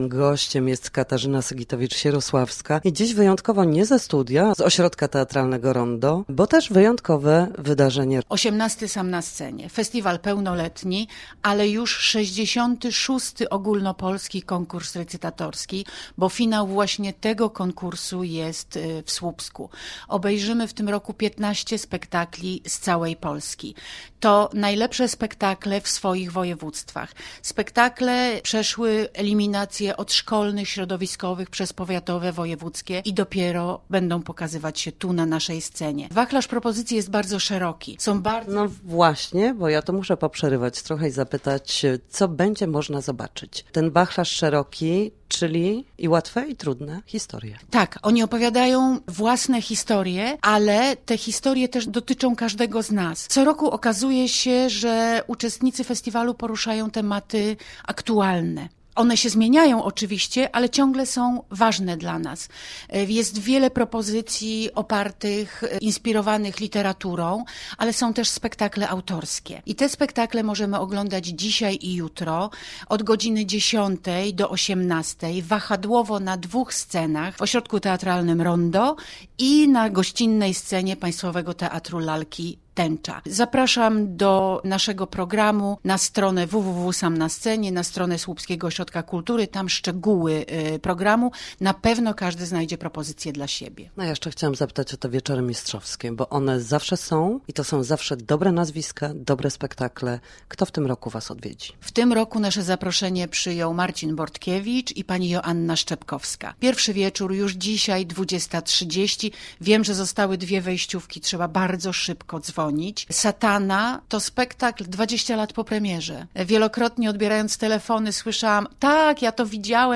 rozmowy